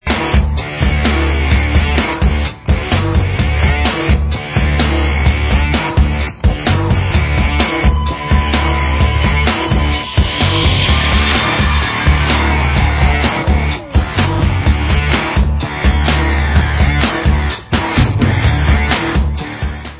Allstar dutch rockband